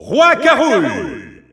Announcer pronouncing King K. Rool in French.
King_K._Rool_French_Announcer_SSBU.wav